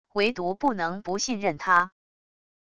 唯独不能不信任他wav音频生成系统WAV Audio Player